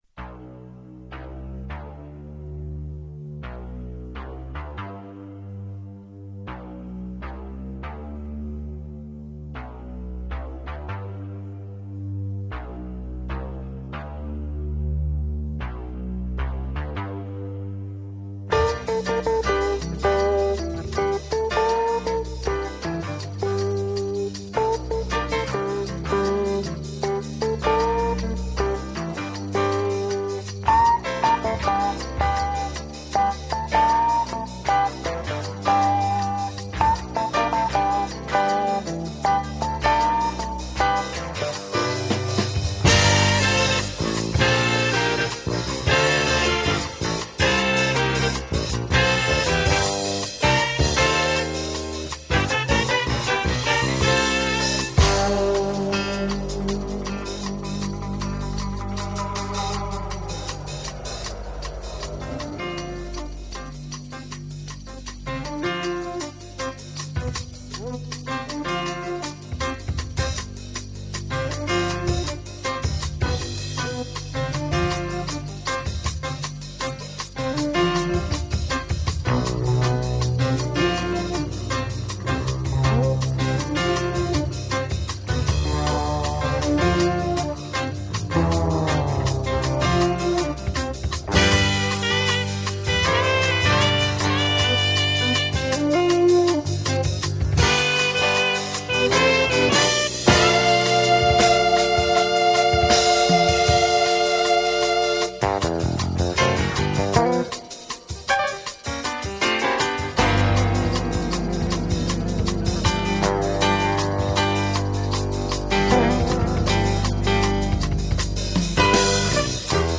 (Stereo) by